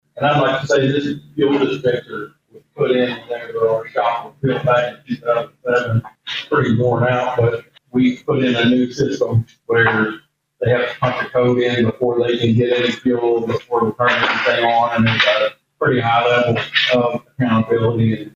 The Board of Osage County Commissioners convened for a regularly scheduled meeting at the fairgrounds on Monday morning.
District Two Commissioner Steve Talburt talked about